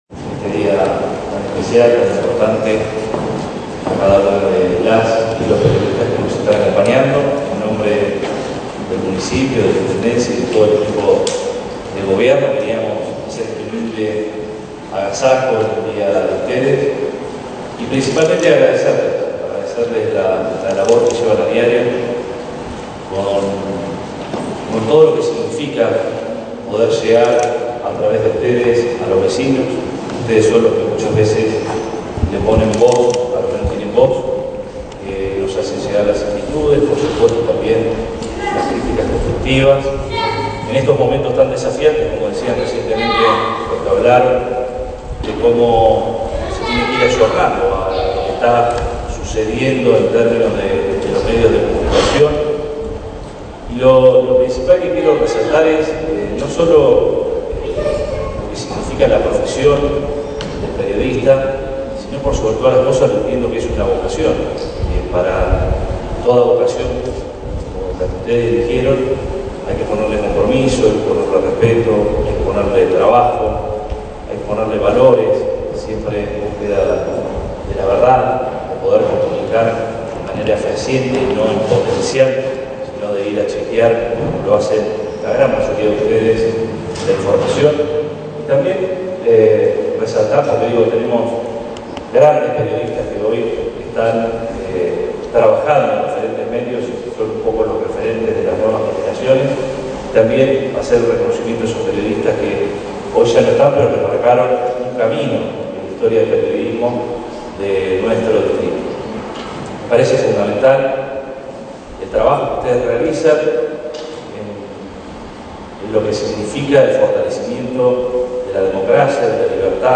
El intendente municipal, Arturo Rojas, encabezó el agasajo que el municipio brindó a la prensa local en el Día del Periodista, cuya fecha 7 de junio está vinculada a la creación del Diario La Gazeta de Buenos Ayres en 1810, obra del secretario de la Primera Junta, Mariano Moreno.
07-06-AUDIO-Arturo-Rojas.mp3